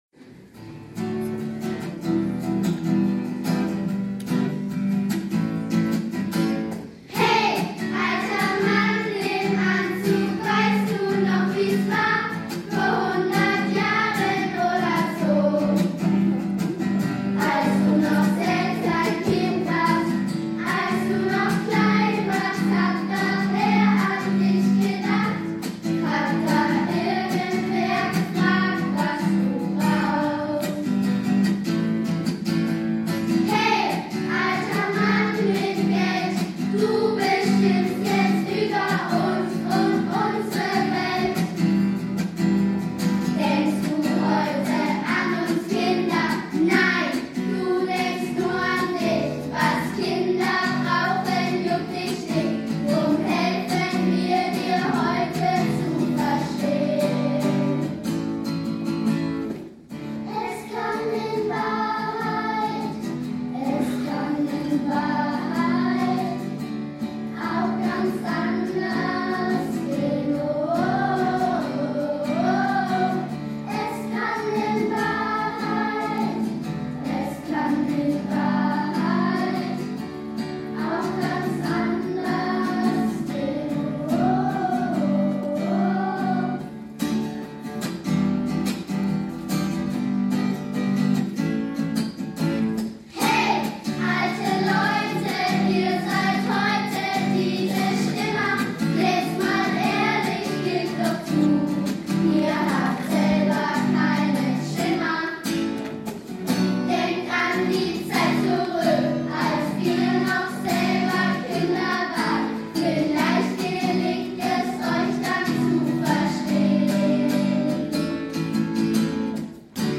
Gastspiel bei der Kinderrechte Gala
13 Kinder und deren Eltern, die wir für dieses Vorhaben begeistern konnten, spendeten sehr motiviert ihren Freitagnachmittag.
Als erste Gage gab es neben einem tosenden Applaus Kinokarten für einen gemeinsamen Kinoausflug.